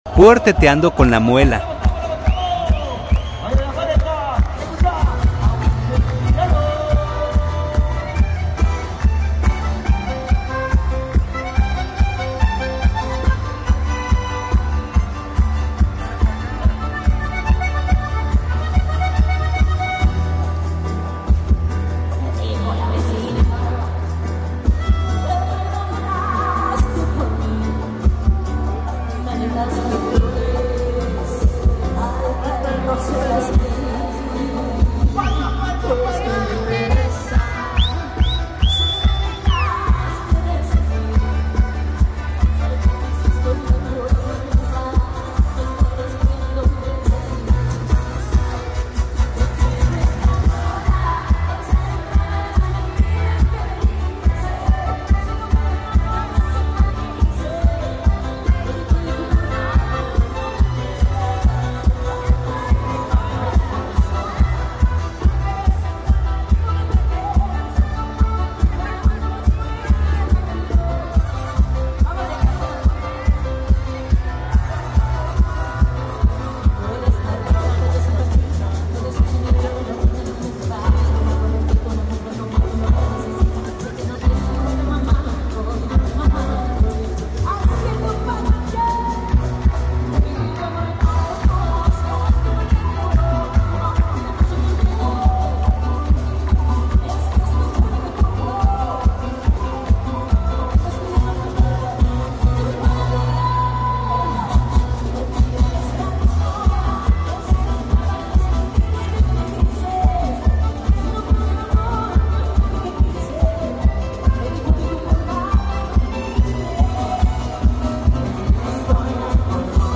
VIE 22 AGO 2025, LAS PAREJAS STA FE.